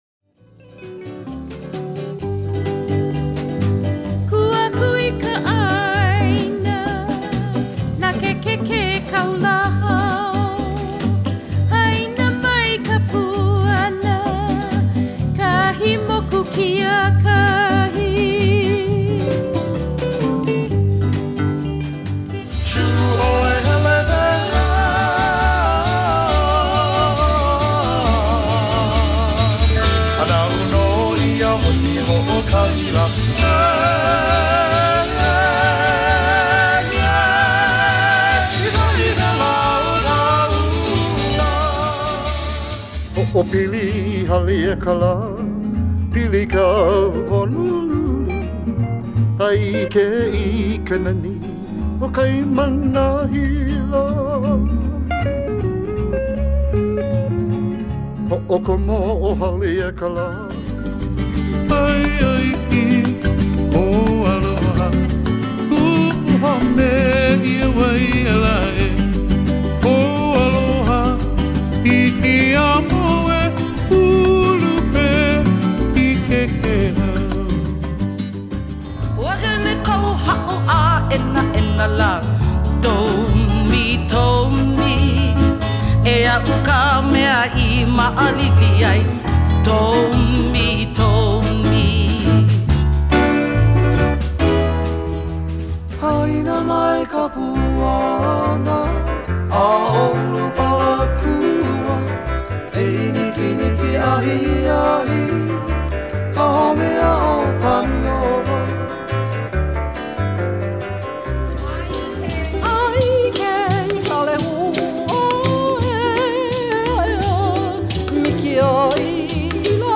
Classic Hawaiian